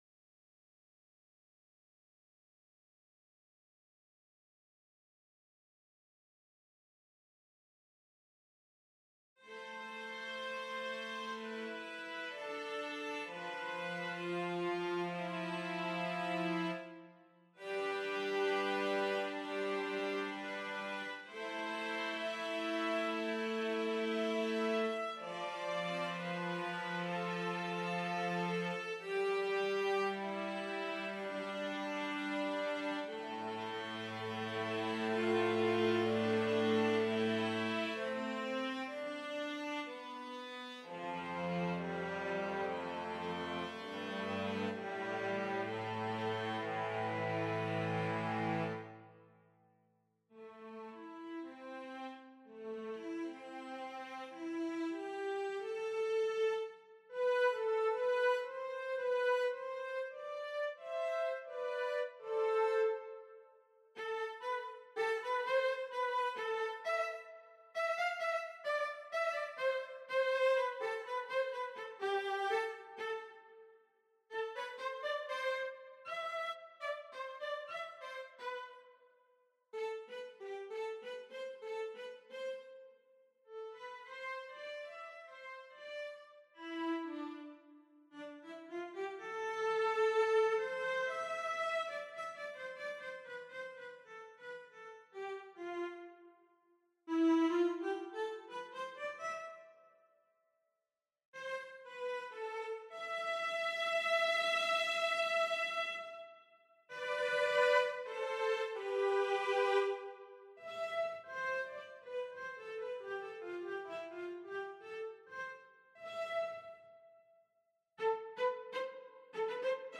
Hier mal auf die Schnell ein unbearbeitetes Cello. Ich variiere dann ab 0:50 halt mit diesen Artikulationstasten (Sustain, Legato, Tremolo, Pizi usw.)